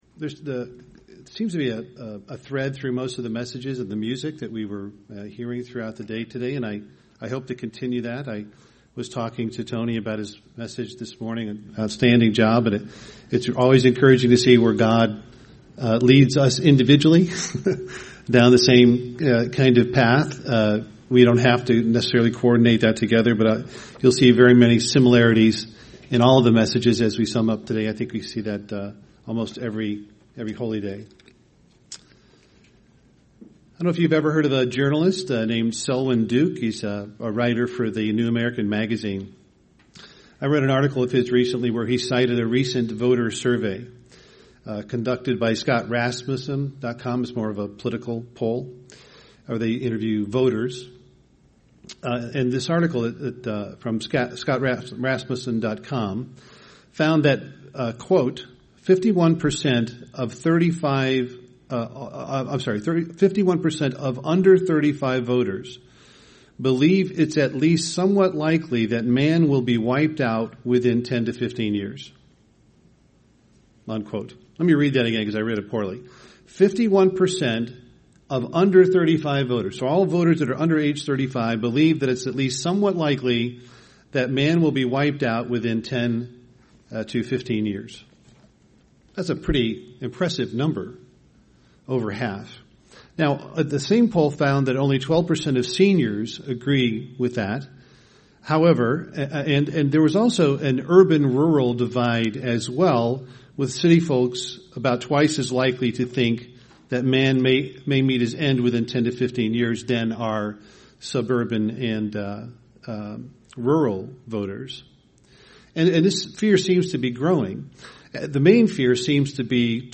UCG Sermon Feast of Trumpets olivet prophecy Firstfruits Studying the bible?